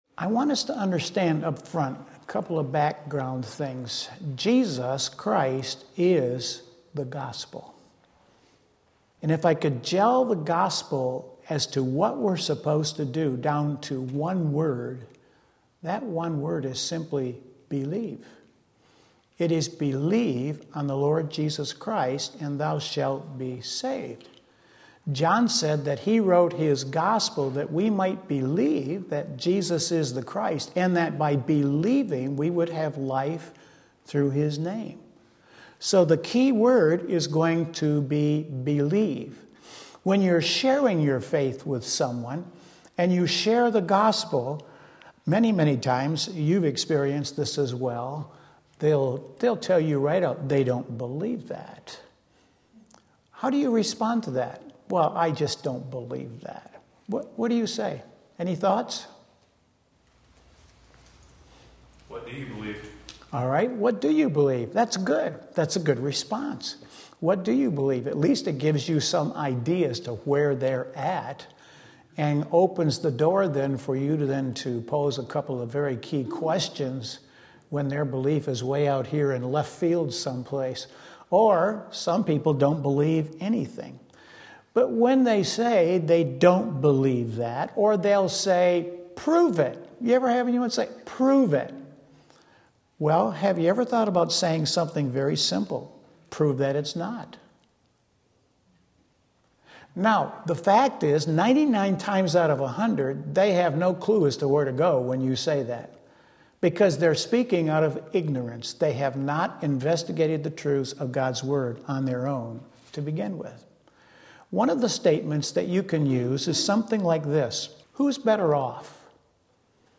Sermon Link
Wednesday Evening Service